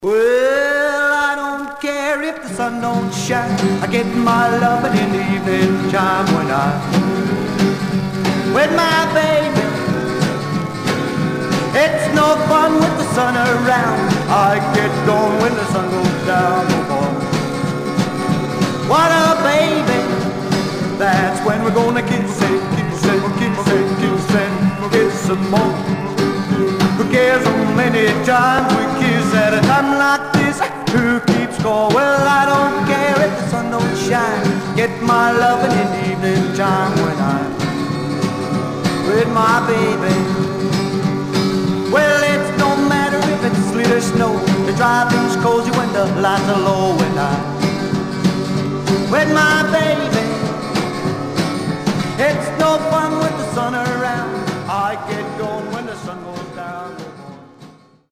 Surface noise/wear Stereo/mono Mono
Rockabilly